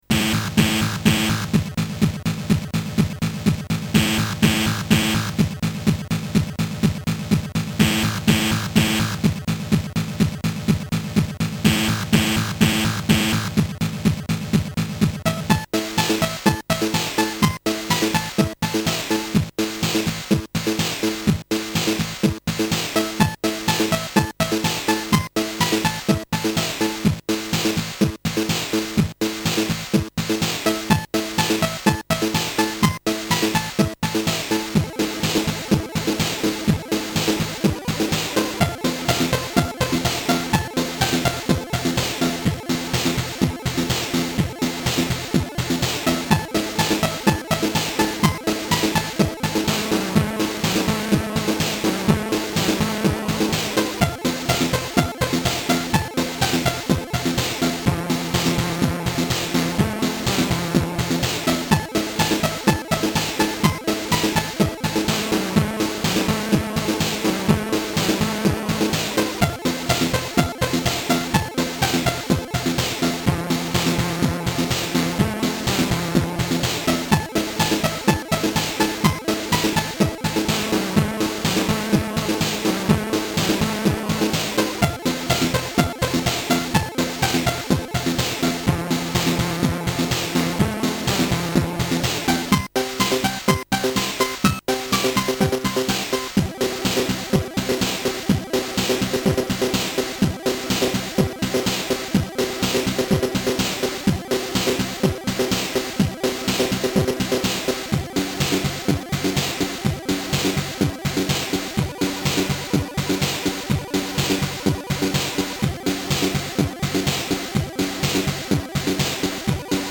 Ok. Nagrane wprost z Atari:
Delikatnie większa częstotliwość mixu.